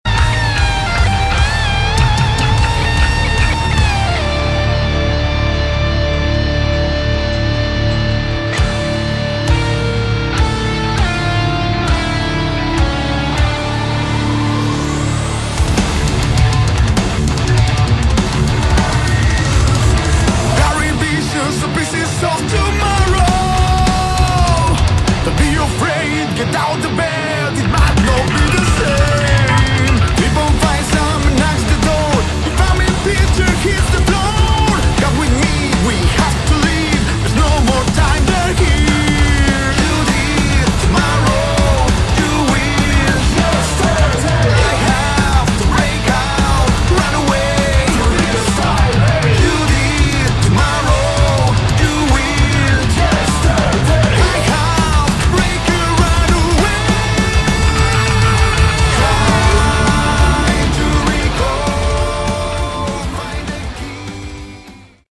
Category: Rock
drums
electric bass
electric guitar
vocals